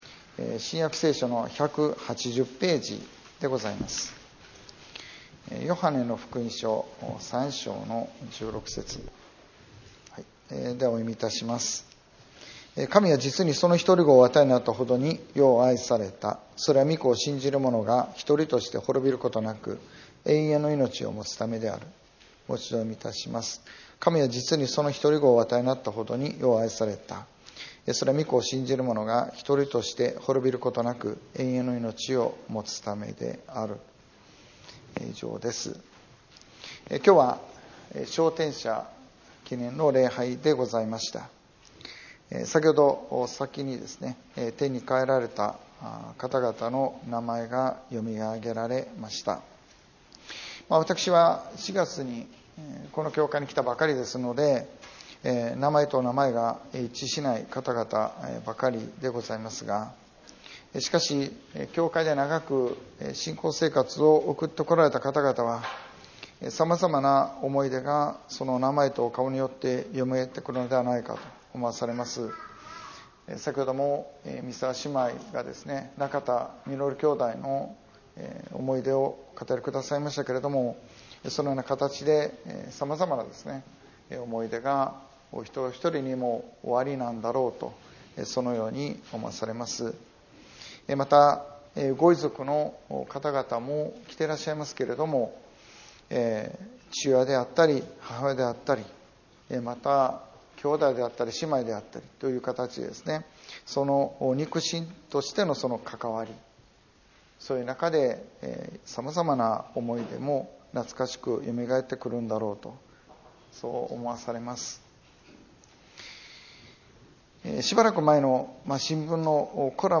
2025年11月23日(召天者記念礼拝) 礼拝説教「聖書の語る永遠のいのち」